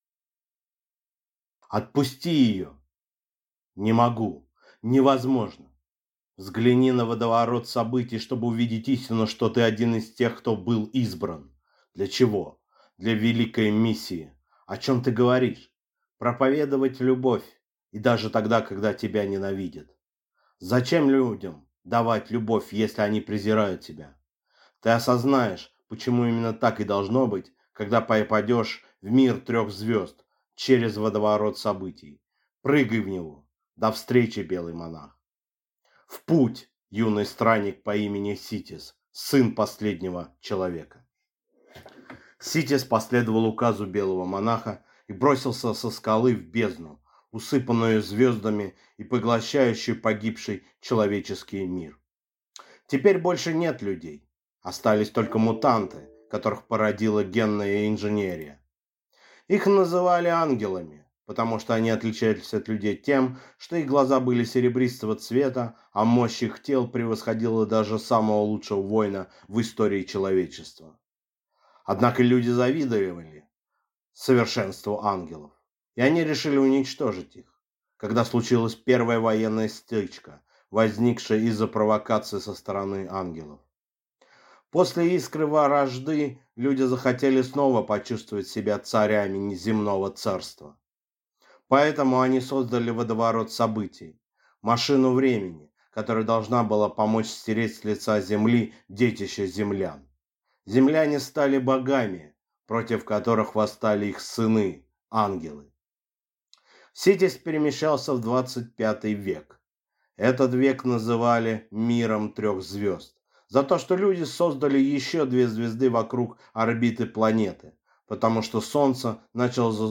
Aудиокнига Невозможное